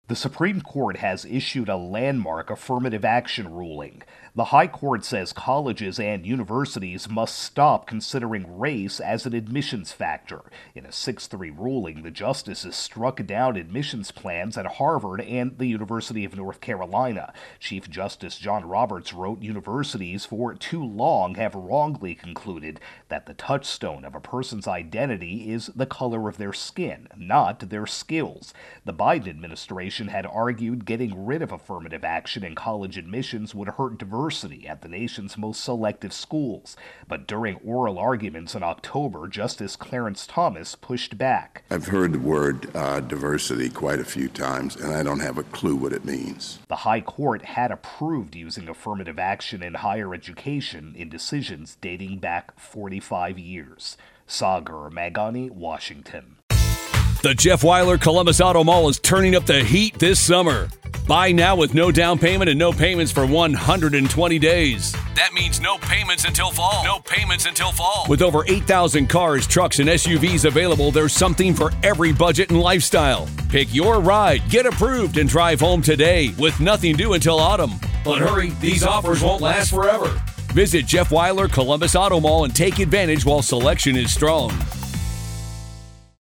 reports on Supreme Court-Affirmative Action.